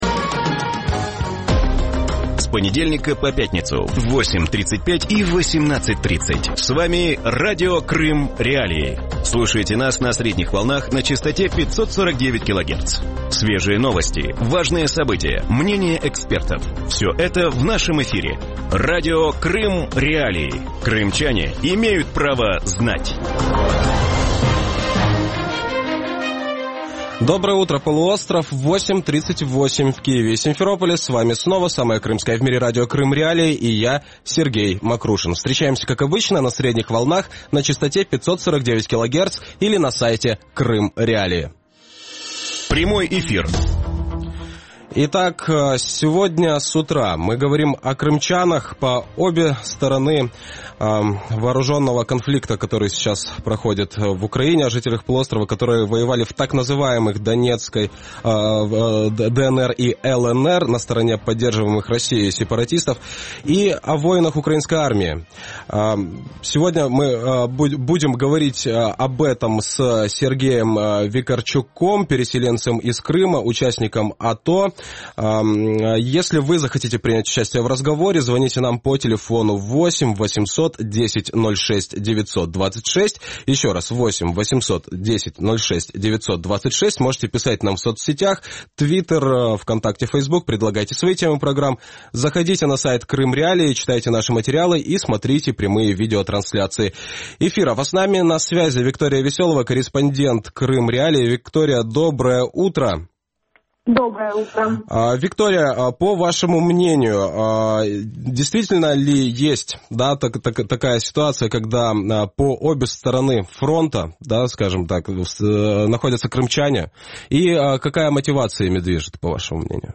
Слушайте нас на средних волнах на частоте 549 килогерц.